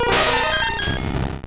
Cri de Mew dans Pokémon Or et Argent.